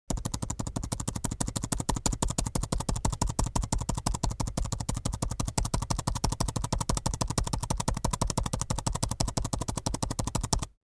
دانلود صدای کیبورد 14 از ساعد نیوز با لینک مستقیم و کیفیت بالا
جلوه های صوتی